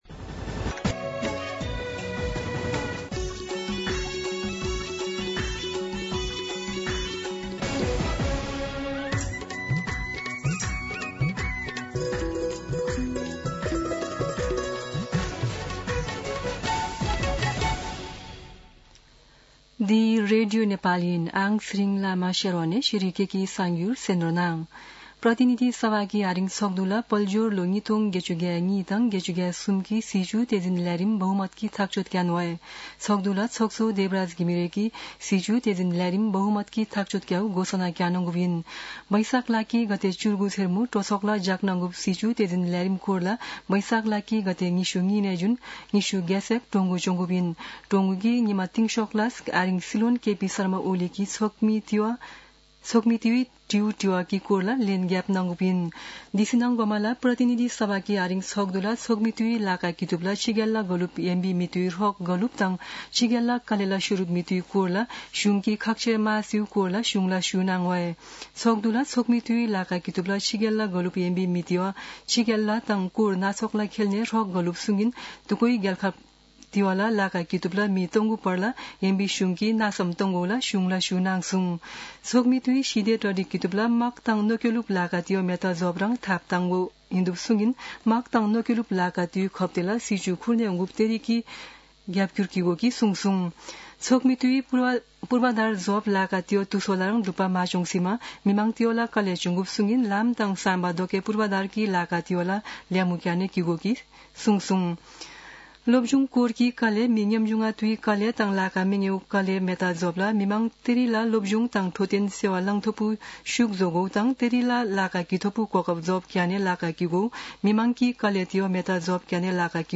शेर्पा भाषाको समाचार : २८ वैशाख , २०८२
Sherpa-News-2.mp3